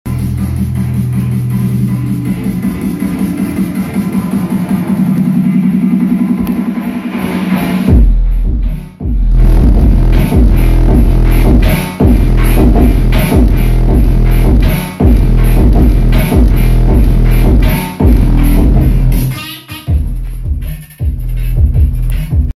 EXTREME BASS TEST, NO BASS Sound Effects Free Download